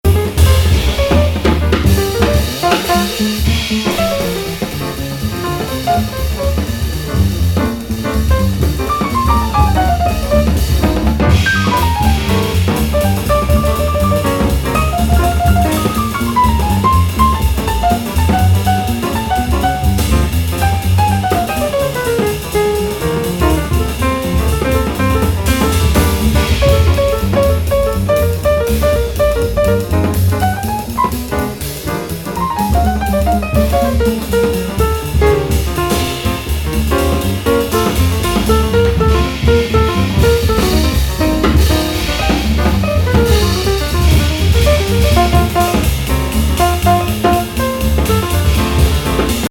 即興のオリジナル・ブルース